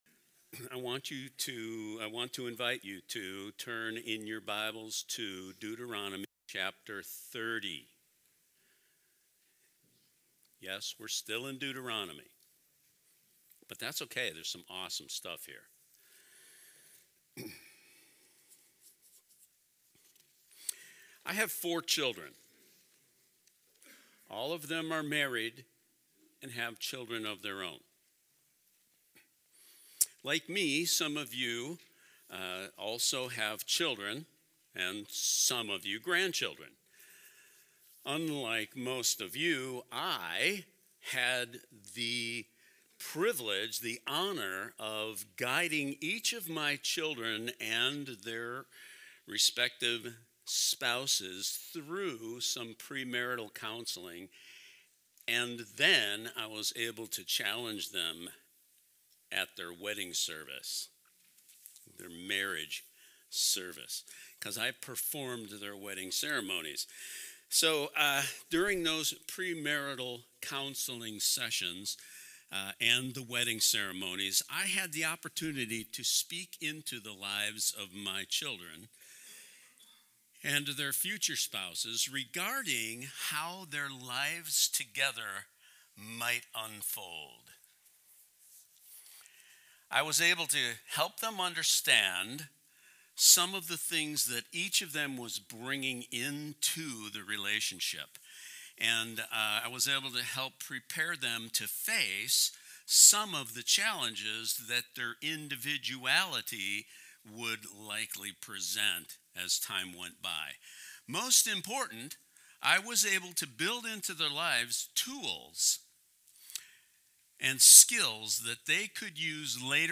This message invites us to listen closely for the voice of the Shepherd—God’s guiding presence that is nearer than we often realize. Drawing from Deuteronomy 30, it reminds us that God hasn’t left us guessing about how to follow Him; He has placed His Word in our hearts and His Spirit within us to lead us. The sermon challenges us to reflect on what our lives are truly saying and whether we’re responding to God’s voice with trust and obedience.